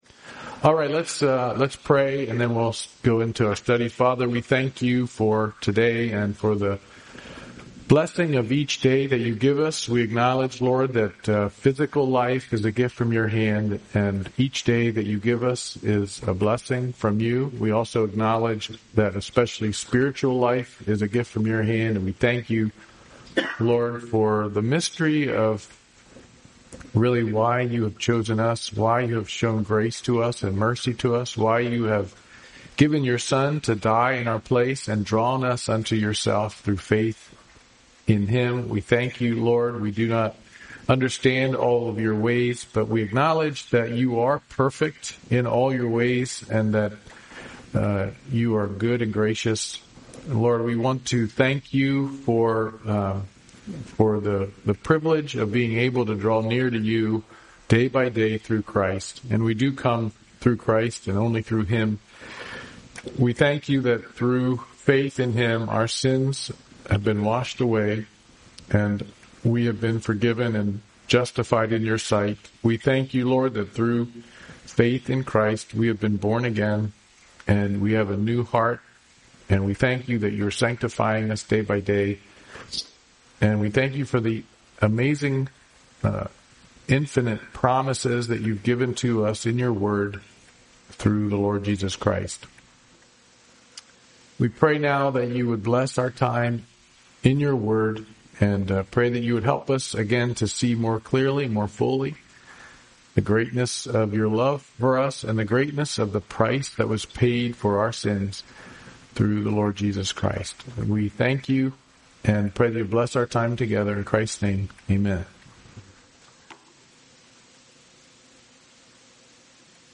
The Work of Christ Service Type: Men's Bible Study Topics